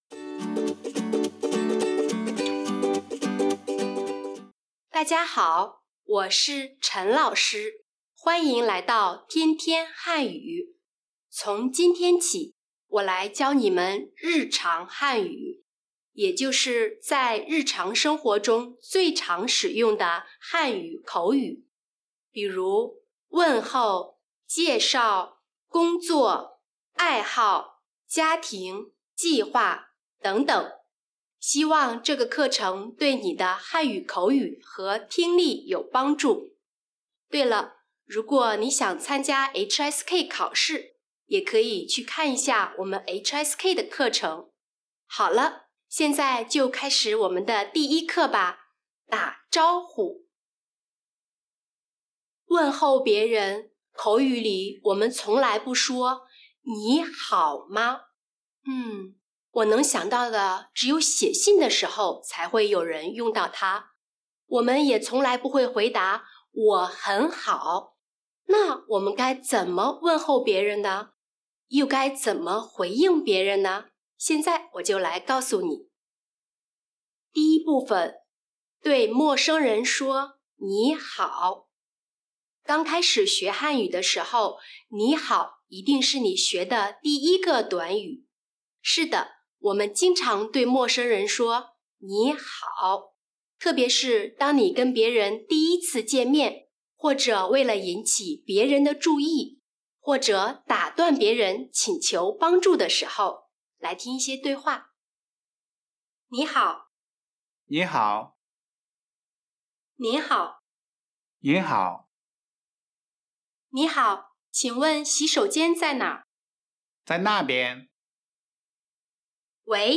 Spoken Chinese Training Lesson 1: Greeting Like Native Speakers